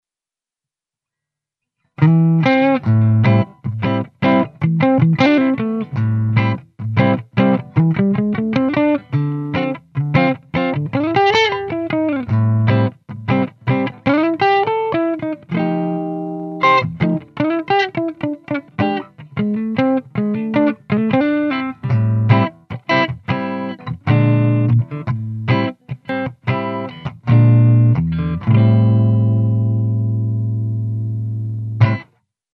Vi lasciamo all’ascolto di alcuni sample audio che non hanno la pretesa di essere esaustivi ma soltanto mostrare alcune tra le tante applicazioni possibili.
Guitar
Guitar.mp3